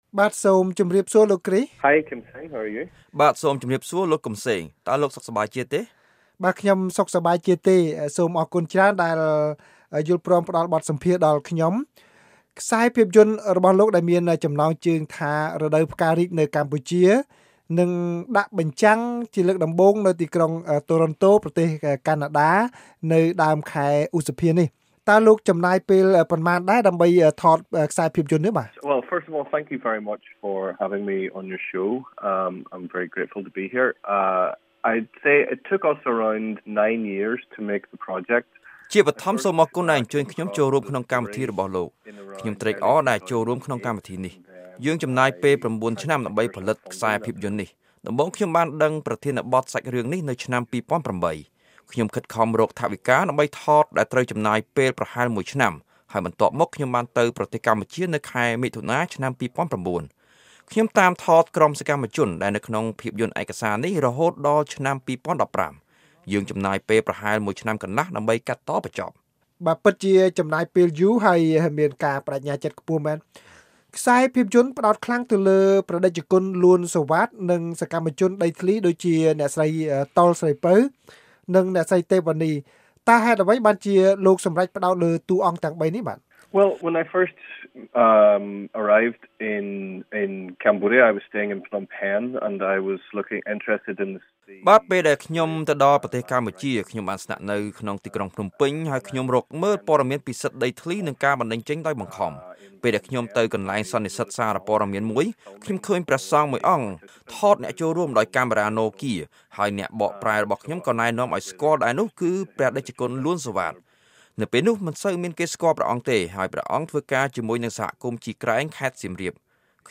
សូមលោកអ្នកនាងស្តាប់បទសម្ភាន៍នេះជាខេមរភាសាទាំងស្រុងដូចតទៅ៕